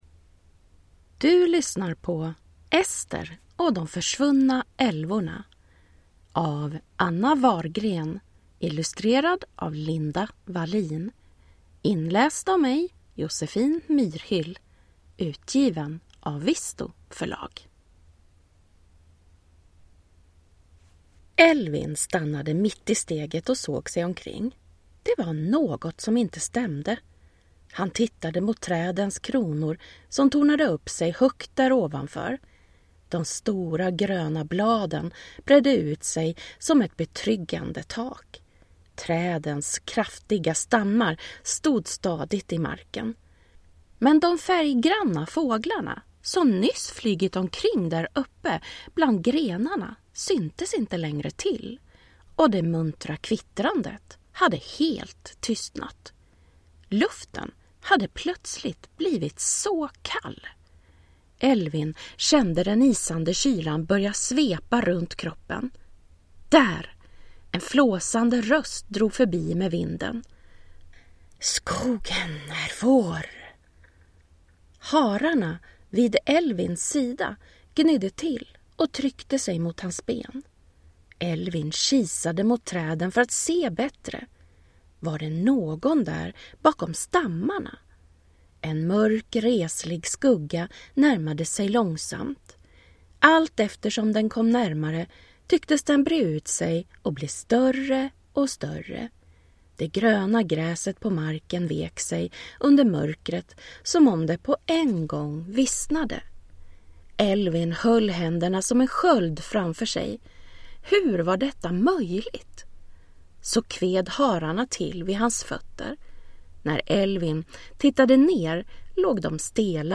Ester och de försvunna älvorna – Ljudbok